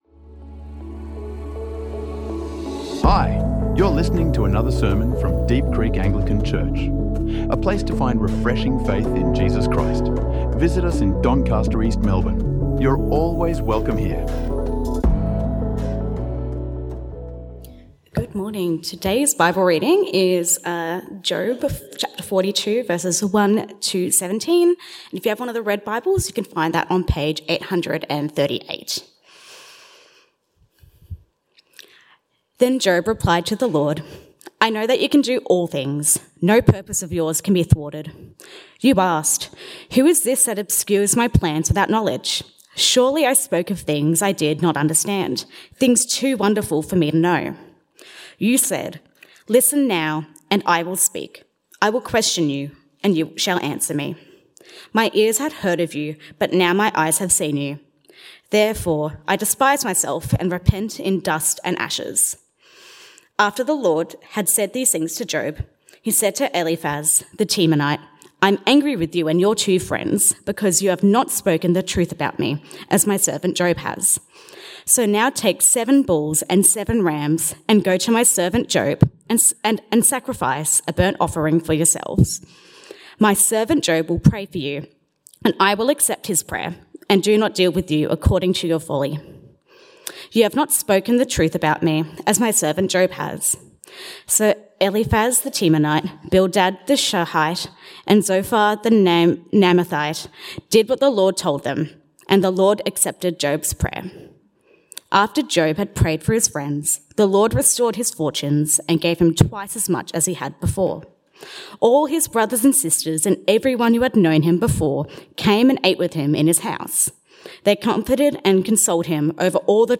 In this final sermon from our Job: The Mystery of Suffering series, we explore God's unexpected response to Job’s cries—a revelation not of condemnation, but of connection.